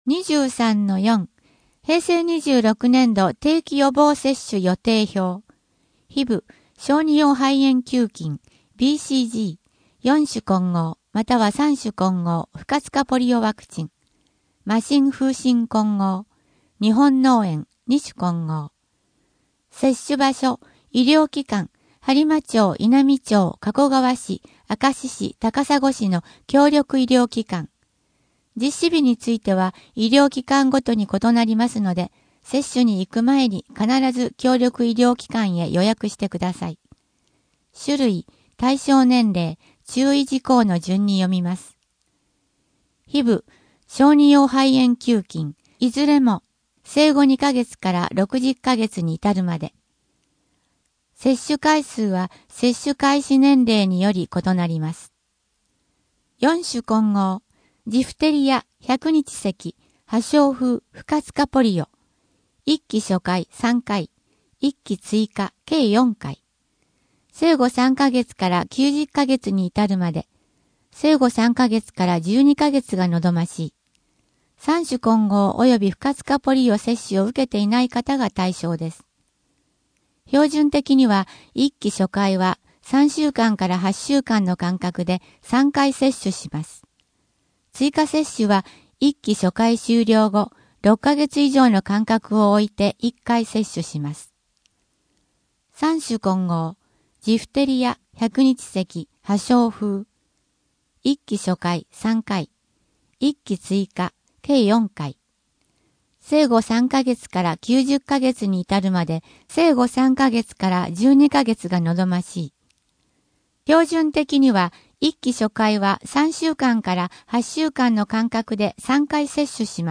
声の「広報はりま」4月号
声の「広報はりま」はボランティアグループ「のぎく」のご協力により作成されています。